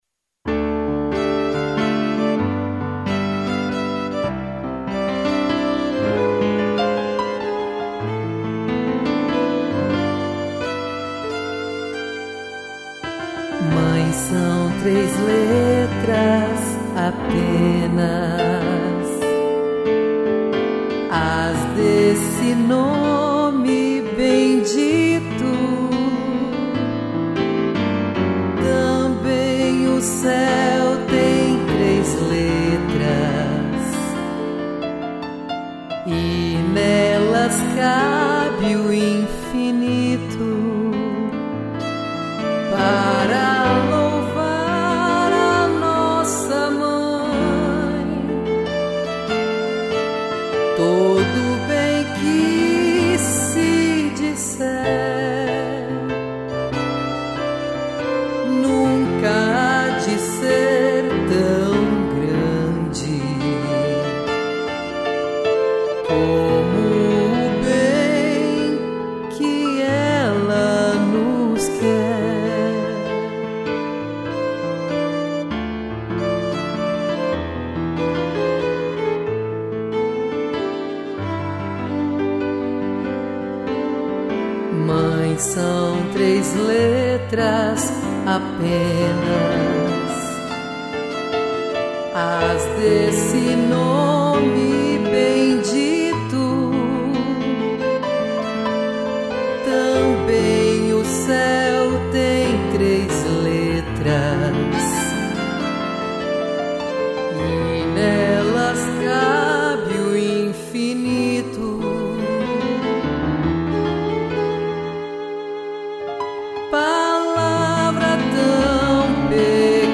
piano e violino